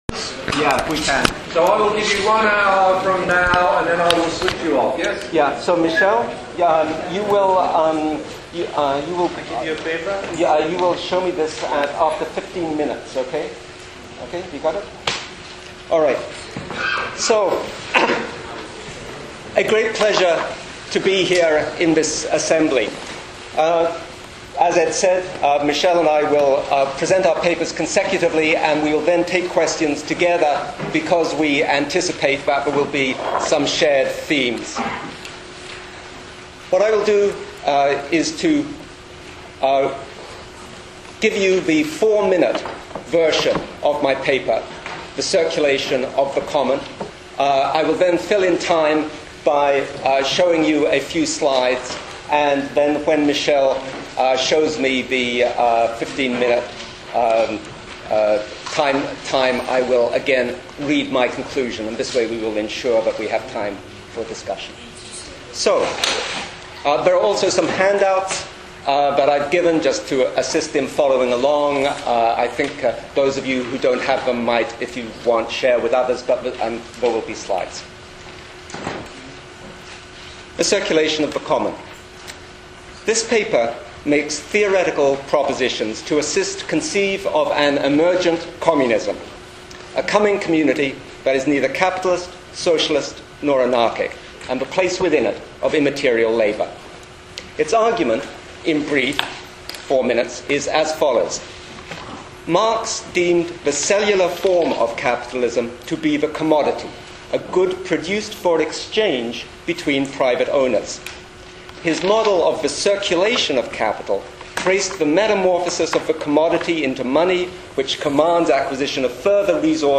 Conference held in Cambridge, UK in April 2006.